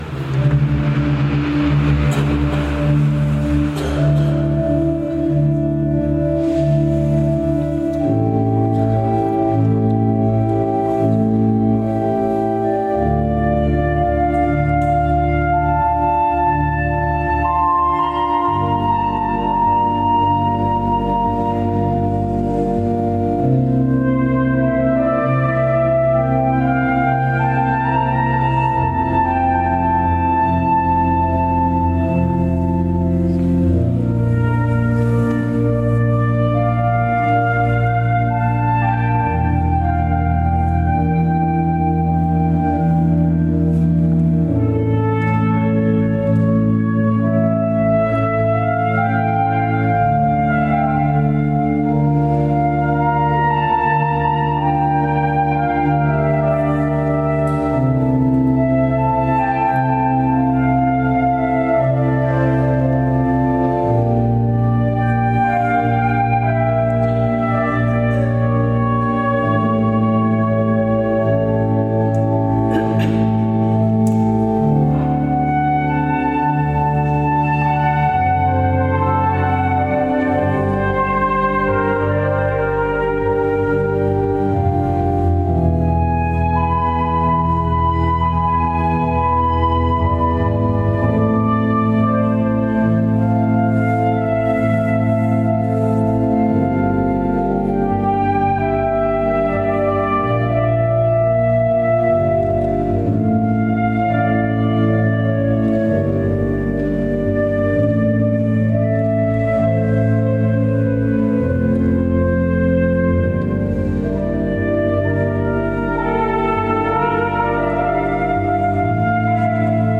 20 settembre concerto NOTE DI SALUTO
flauto.mp3